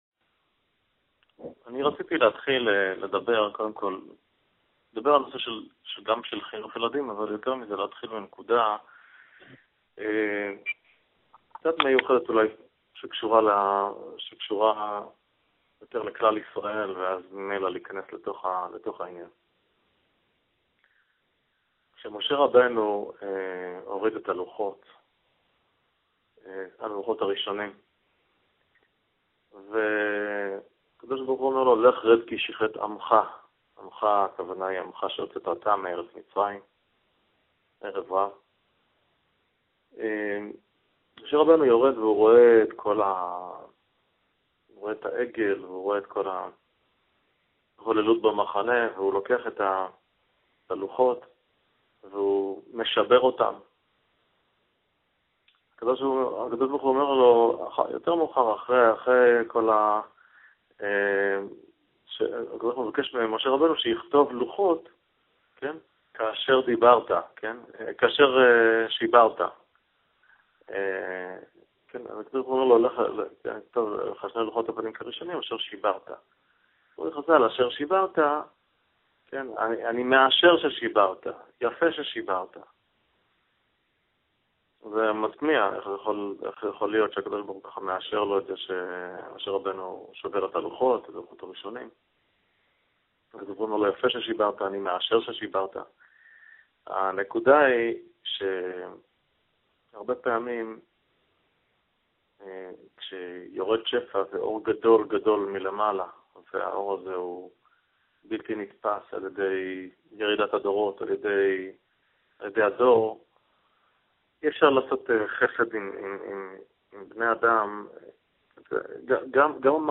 בהרצאה מרתקת על מדע, אמת, תורה וראיות לכך שהתורה קדמה למדע.